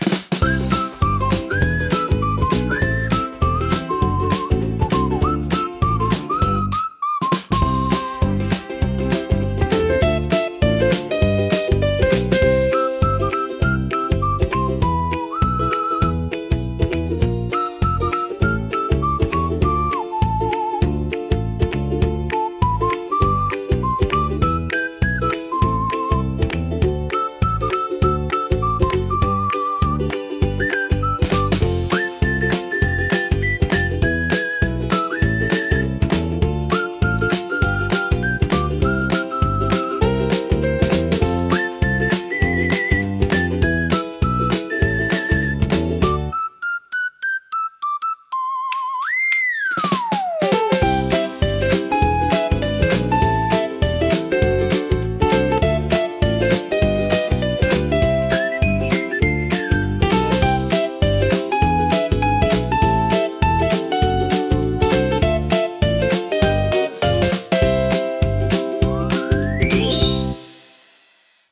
軽快なリズムと遊び心あふれるメロディが、聴く人に楽しさと自由なエネルギーを届けます。
気軽で楽しい空気を作り出し、場を明るく盛り上げるジャンルです。